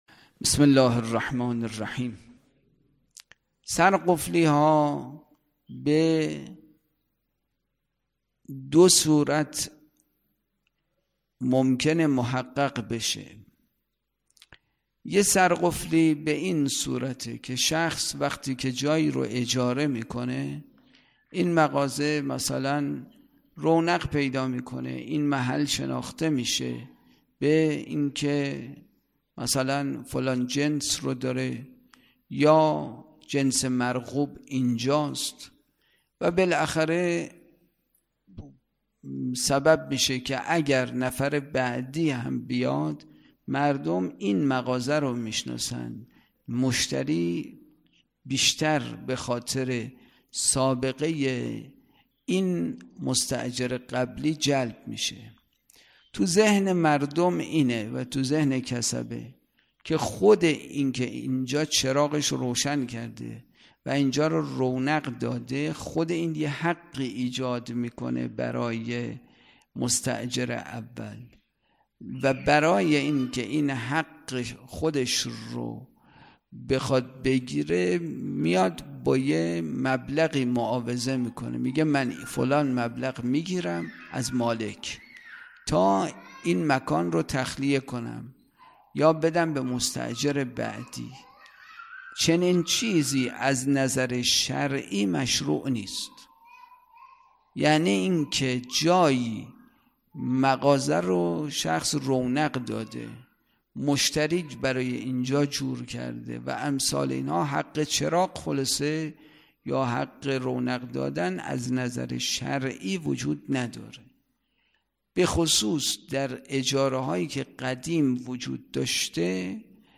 برگزارکننده: مسجد اعظم قلهک